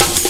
amen chop2snare.wav